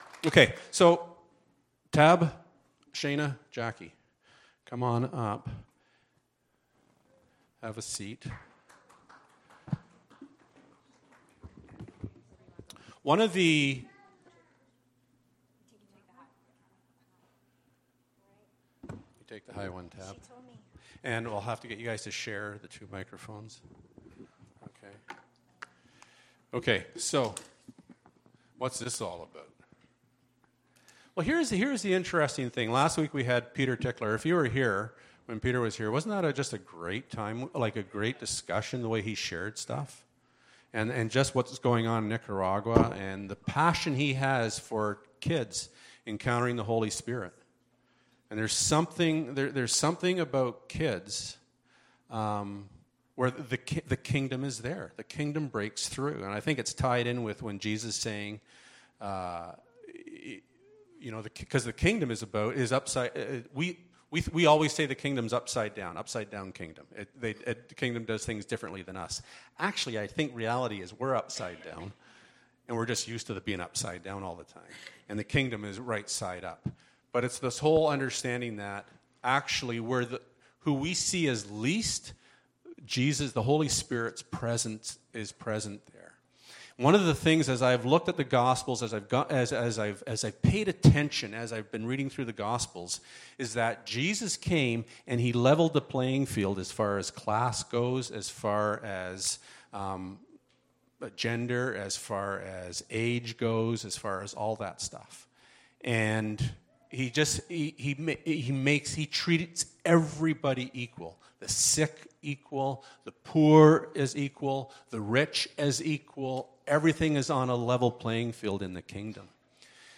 Jesus At The Playground (A panel discussion)
This morning we are going to have a panel discussion about thehonour we have of serving families by serving their children.